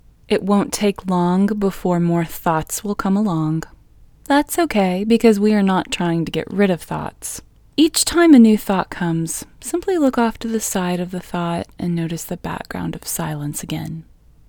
QUIETNESS Female English 9
Quietness-Female-9-1.mp3